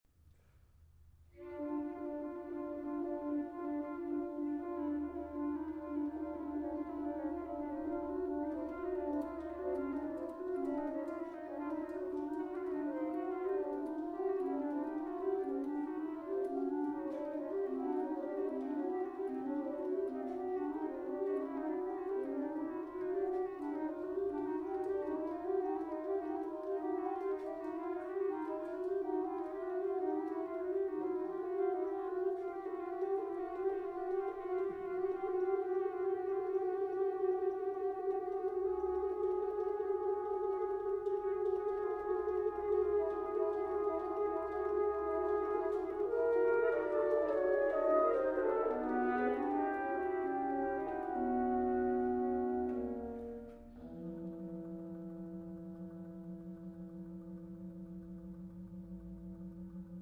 Allegro con delicatezza   2:40